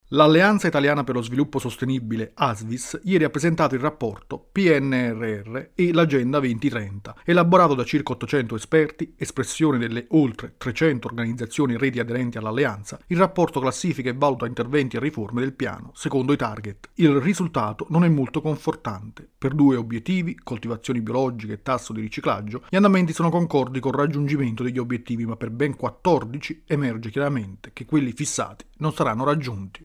Per gli obiettivi dell’agenda 2030 nel Piano di ripresa e resilienza. Il servizio